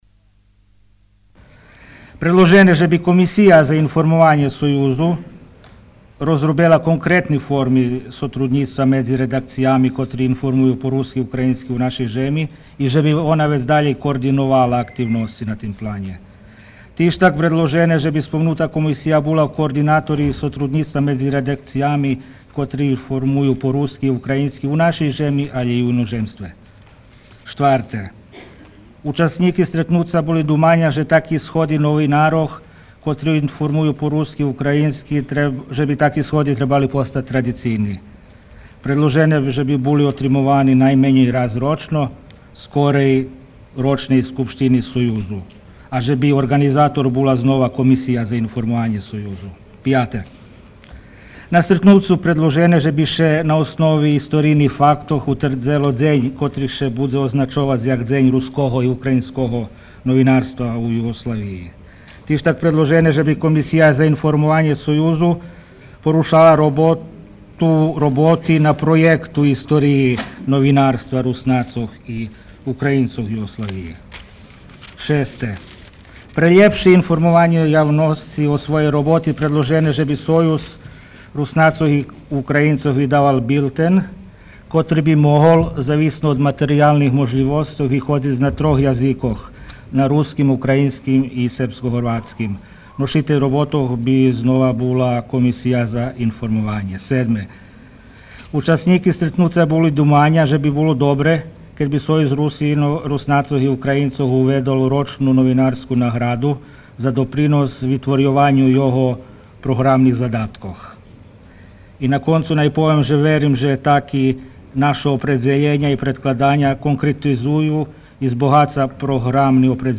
Фоно запис зоз сновательней схадзки Союзу